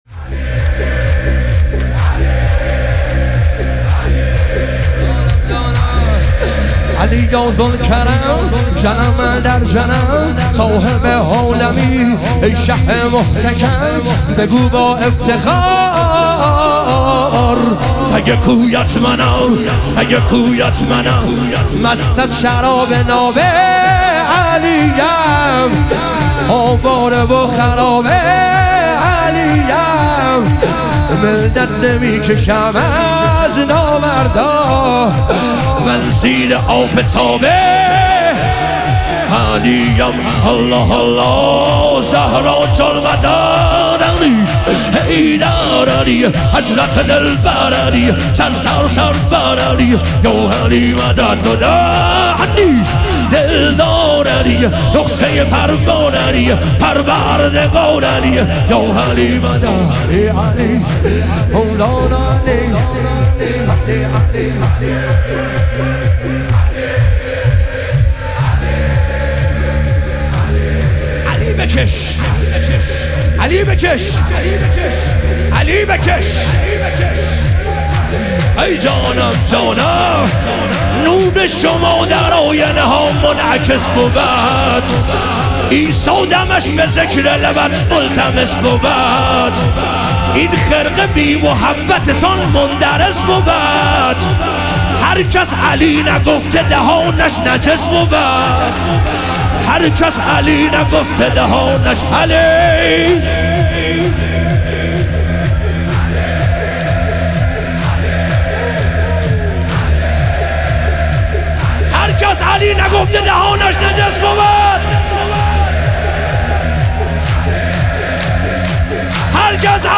لیالی قدر و شهادت امیرالمومنین علیه السلام - شور